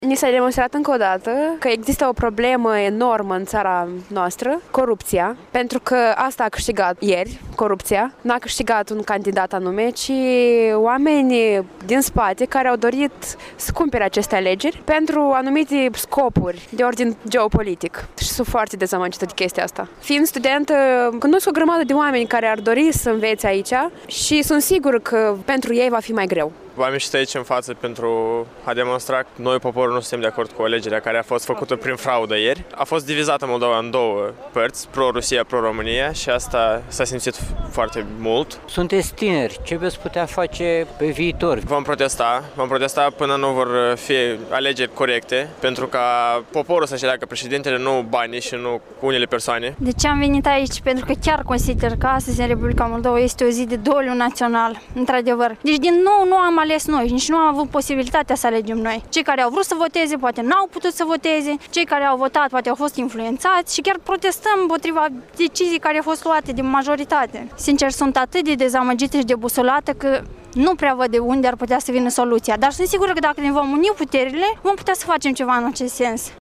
14-nov-rdj-20-vox-pop-Iasi.mp3